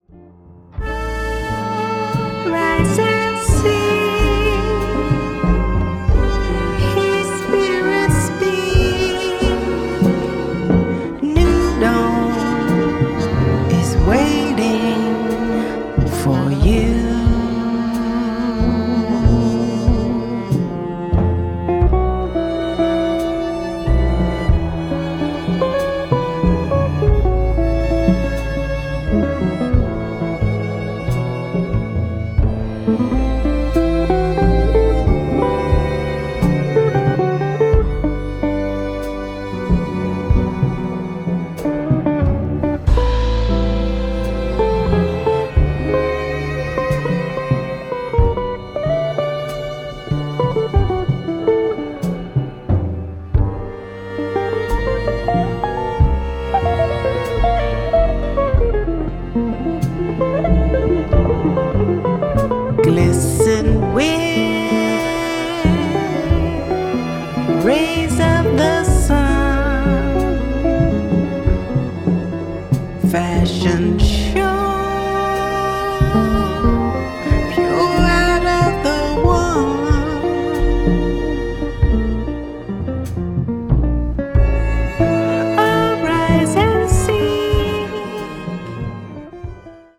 全編通して温かく豊かな味わいに満ちた仕上がりとなっています。